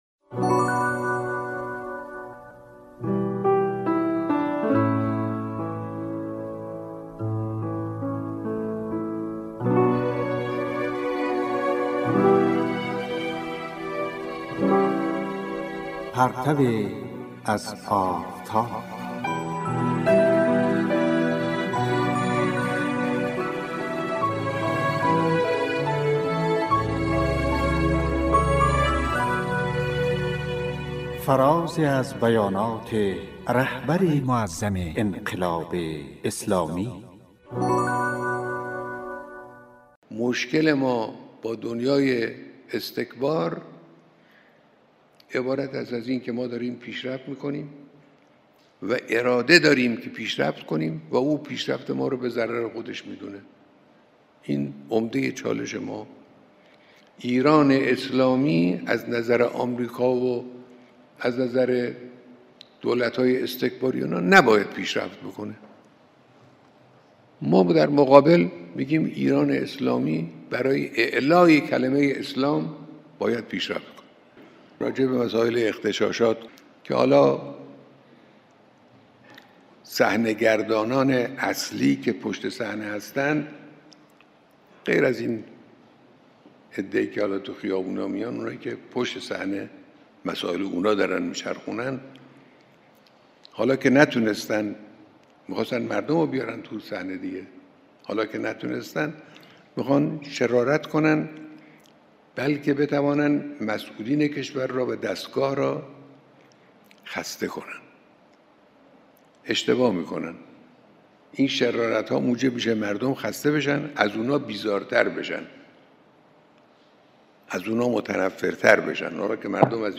"پرتویی از آفتاب" کاری از گروه معارف رادیو تاجیکی صدای خراسان است که به گزیده ای از بیانات رهبر معظم انقلاب می پردازد.